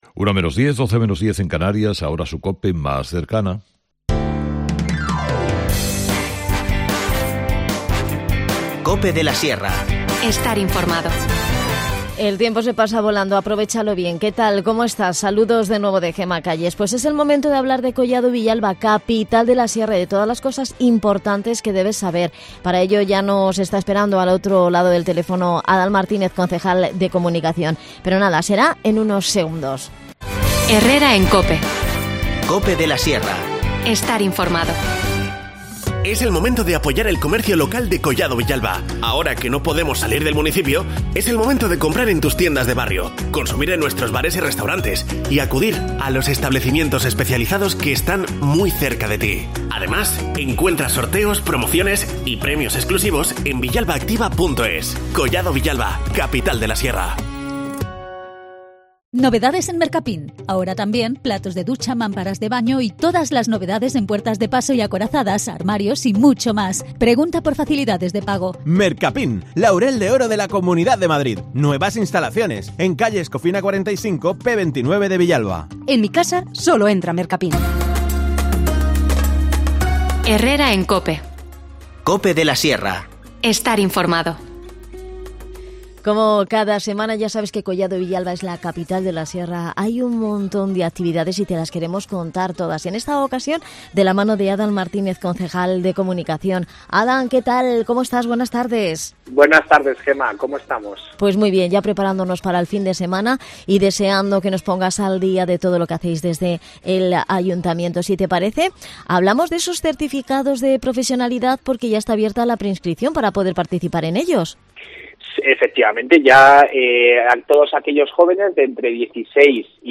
El Ayuntamiento de Collado Villalba, desde el Área de Formación y Empresas, ha abierto la preinscripción de nuevas acciones para la obtención de Certicados de Profesionalidad. Estos cursos gratuitos y con prácticas en empresas van dirigidos a jóvenes de entre 16 y 30 años de edad que estén inscritos en el programa de Garantía Juvenil. Nos cuenta todos los detalles Adan Martínez, concejal de Comunicación